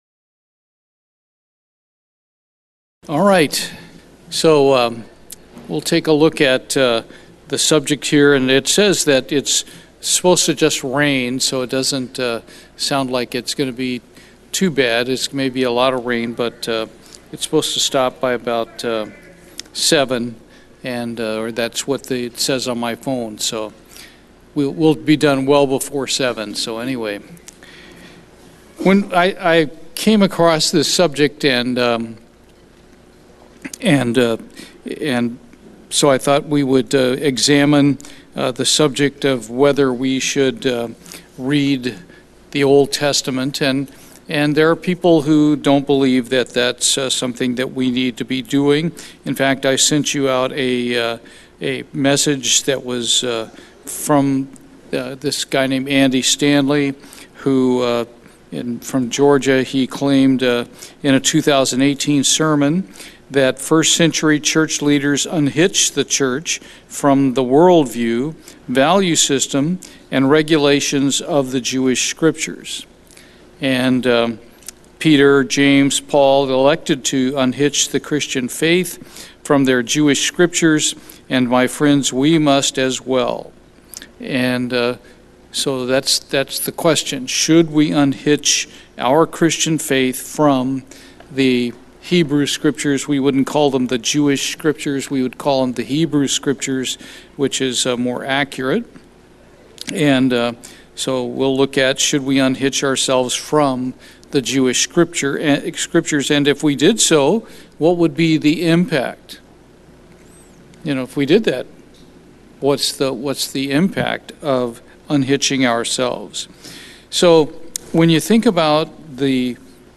Bible Study - As Christians, Should We Read the Old Testament?
Given in Houston, TX